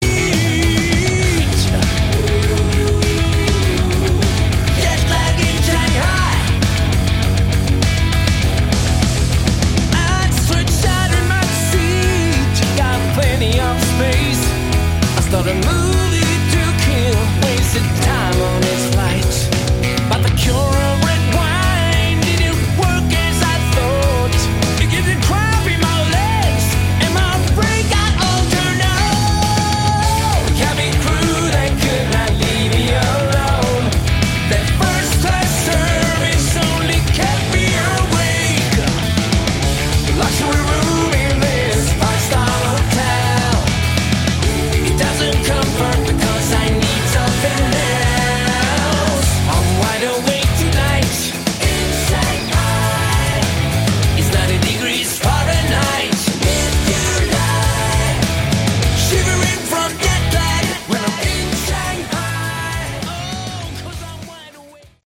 Category: Melodic Rock
guitars, keyboards